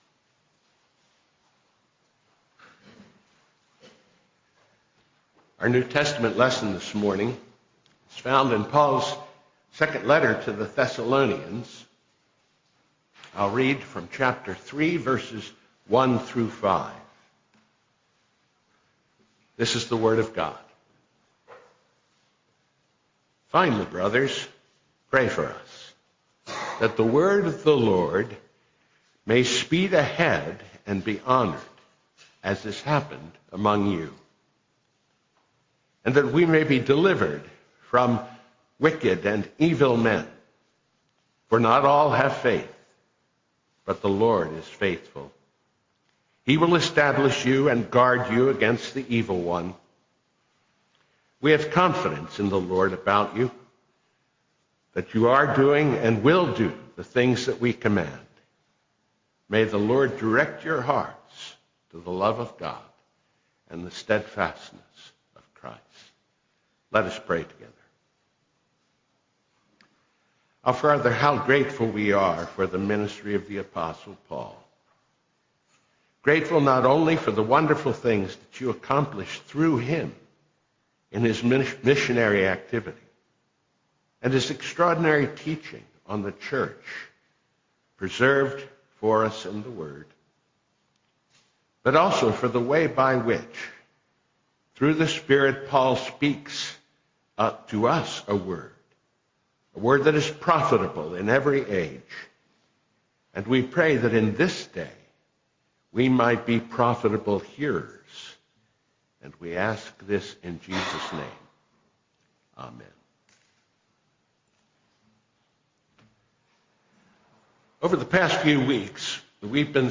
Pray for the Word of God to Make Unimpeded Progress: Sermon on 2Thessalonians 3:1-5 - New Hope Presbyterian Church